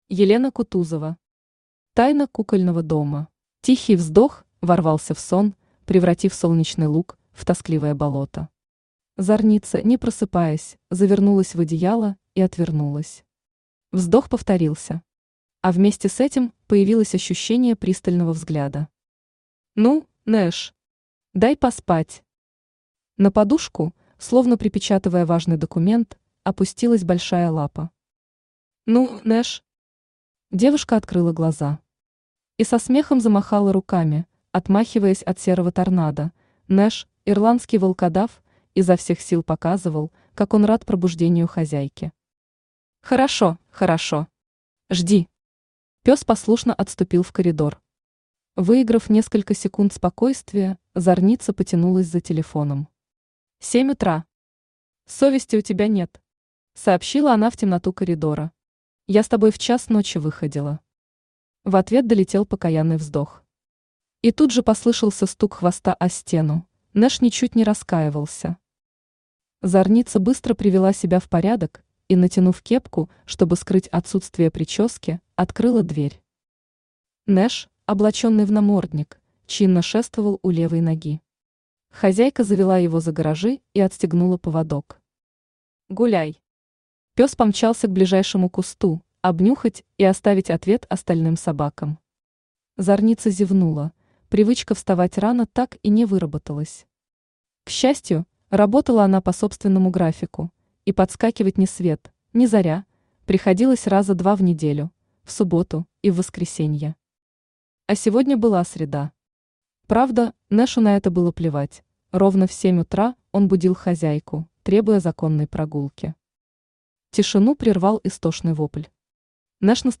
Аудиокнига Тайна «Кукольного дома» | Библиотека аудиокниг
Aудиокнига Тайна «Кукольного дома» Автор Елена Геннадьевна Кутузова Читает аудиокнигу Авточтец ЛитРес.